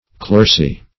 Clerisy \Cler"i*sy\, n. [LL. clericia.